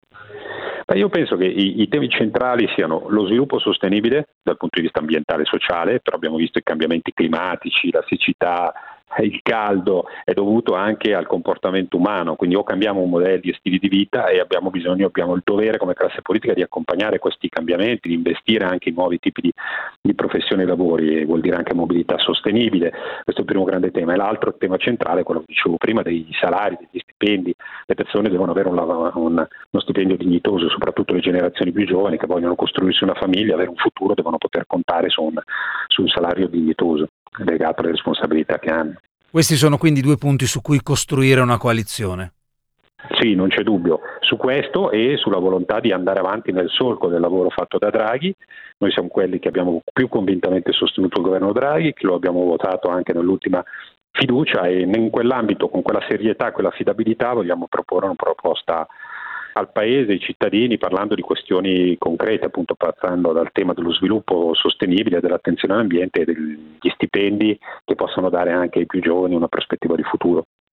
Il senatore del Pd Alessandro Alfieri